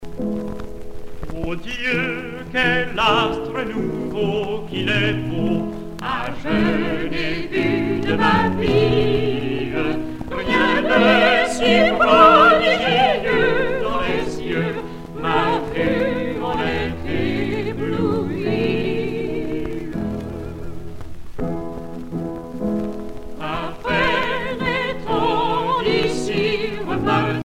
Noël, Nativité
Pièce musicale éditée